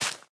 Minecraft Version Minecraft Version 1.21.5 Latest Release | Latest Snapshot 1.21.5 / assets / minecraft / sounds / block / big_dripleaf / break6.ogg Compare With Compare With Latest Release | Latest Snapshot
break6.ogg